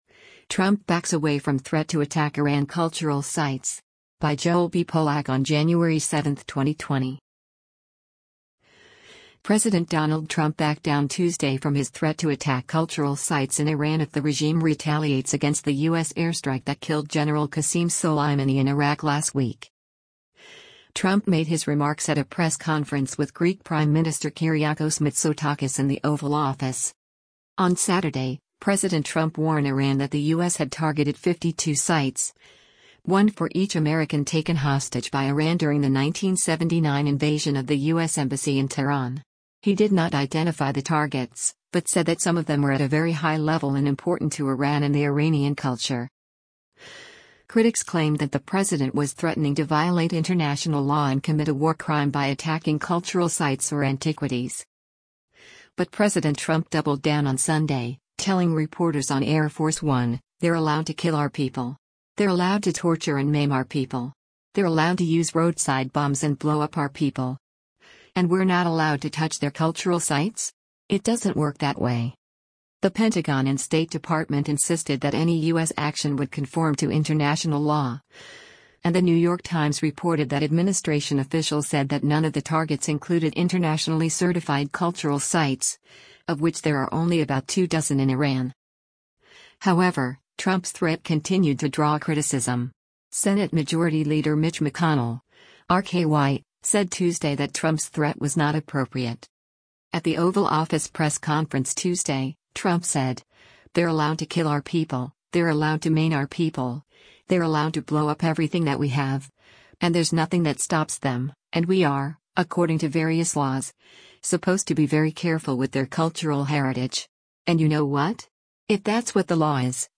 Trump made his remarks at a press conference with Greek Prime Minister Kyriakos Mitsotakis in the Oval Office.